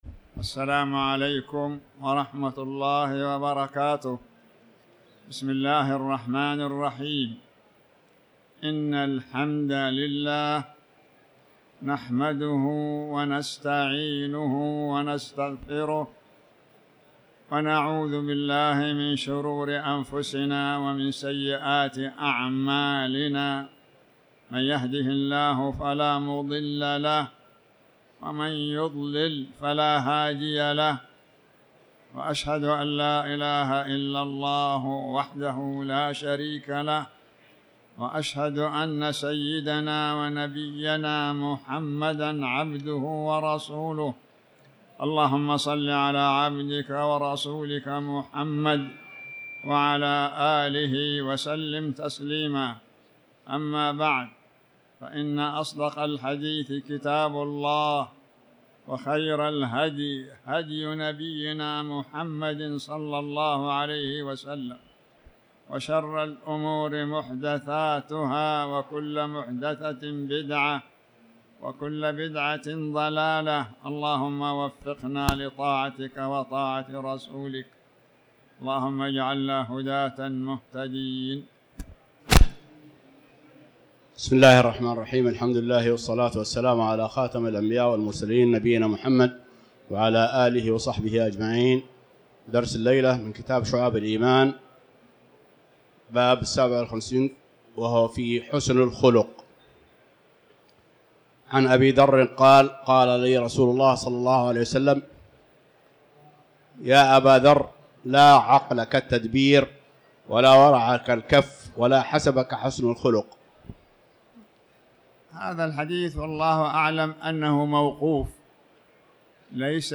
تاريخ النشر ١٥ شوال ١٤٤٠ هـ المكان: المسجد الحرام الشيخ